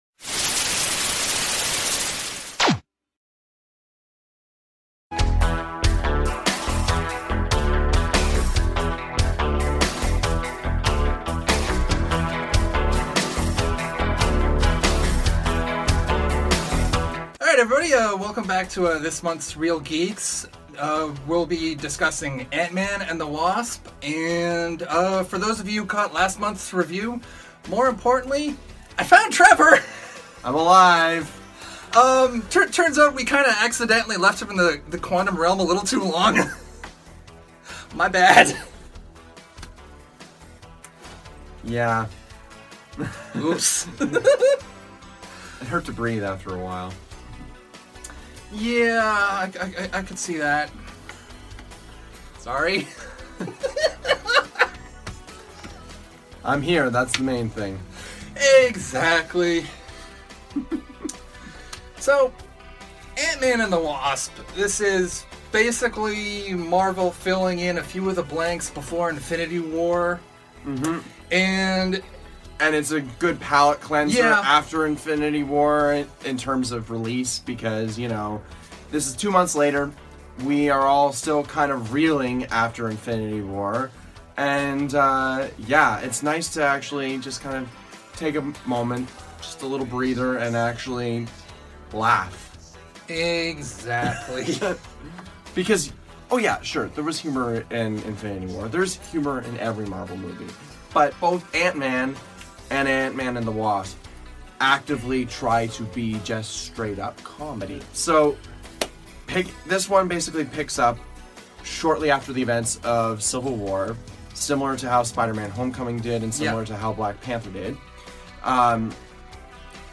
Originally recorded in Halifax, NS, Canada